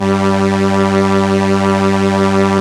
Index of /90_sSampleCDs/Keyboards of The 60's and 70's - CD1/STR_ARP Strings/STR_ARP Solina